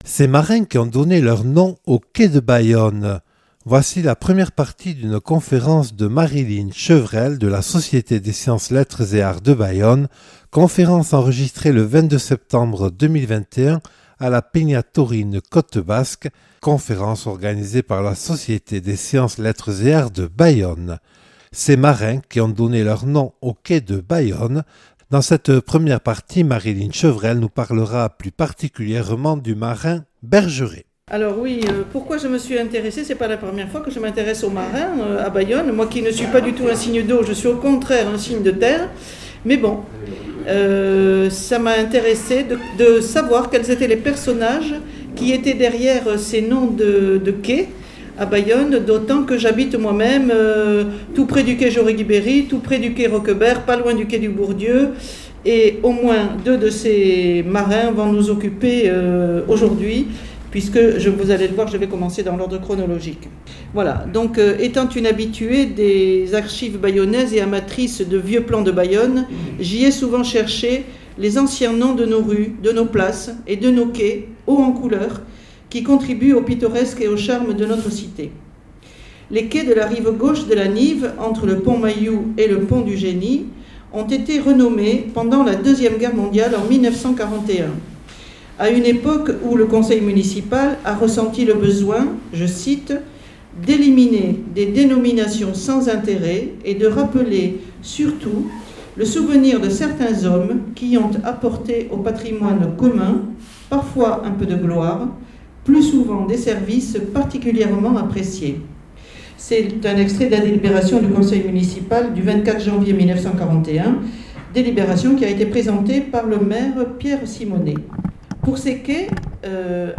(Enregistrée le 23/09/2021 lors de la conférence proposée par la Société des Sciences, Lettres et Arts de Bayonne).